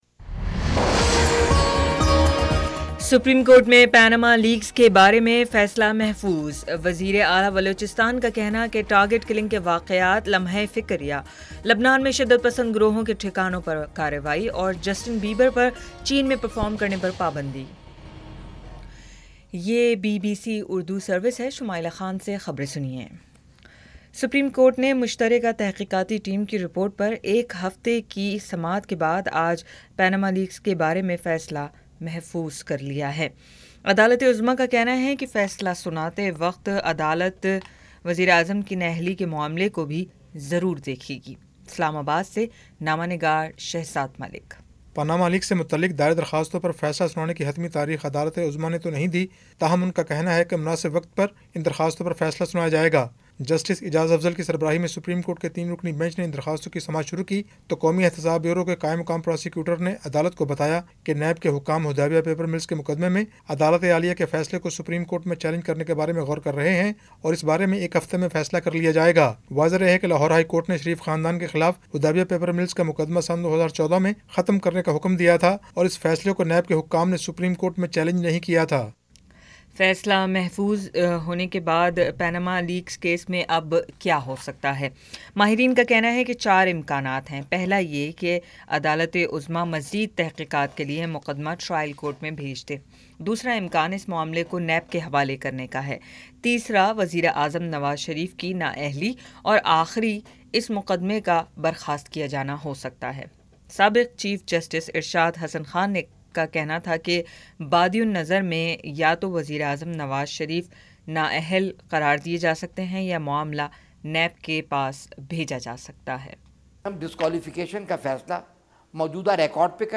جولائی 21 : شام پانچ بجے کا نیوز بُلیٹن